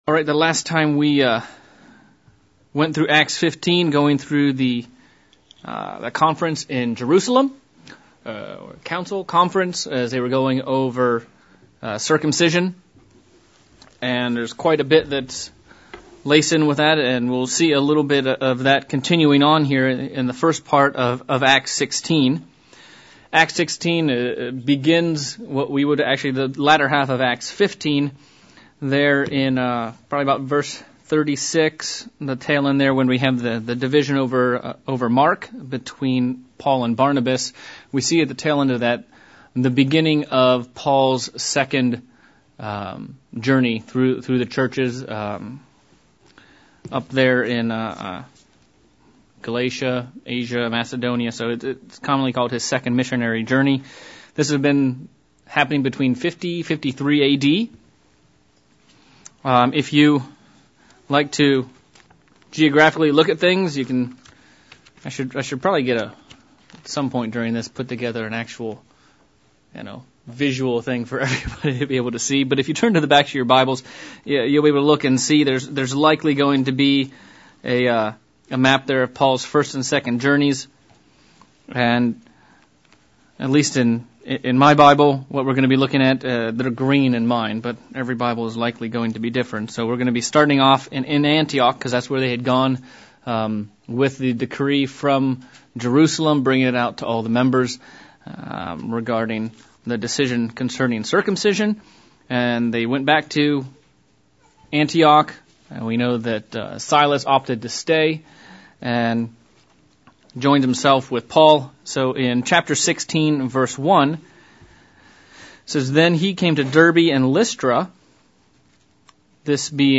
Bible Study: Acts of the Apostles - Chapter 16